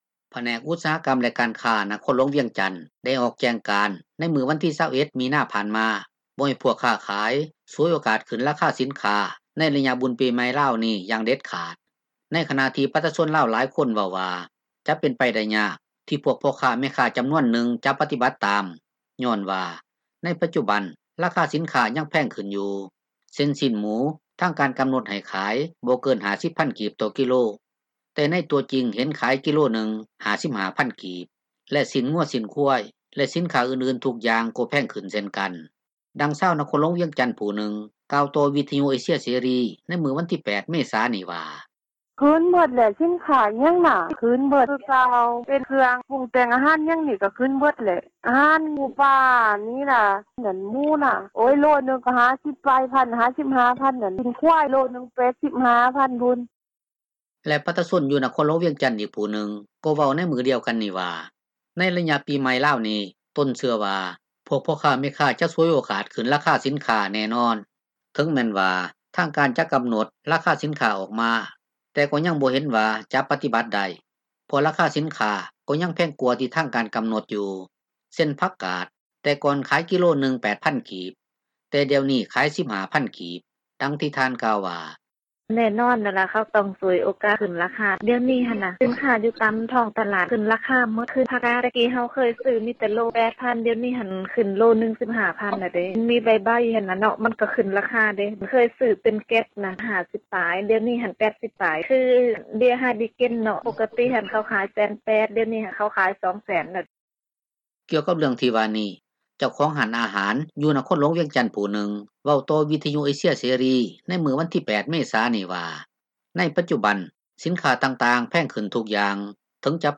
ດັ່ງຊາວ ນະຄອນຫລວງວຽງຈັນຜູ້ນຶ່ງ ກ່າວຕໍ່ວິທຍຸເອເຊັຽເສຣີໃນມື້ງວັນທີ 8 ເມສານີ້ວ່າ: